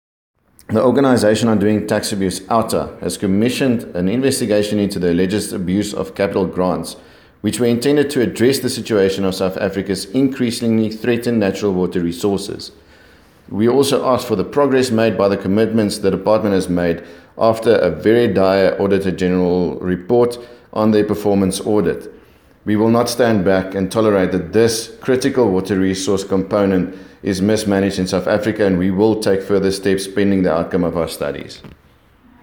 English Sound Bite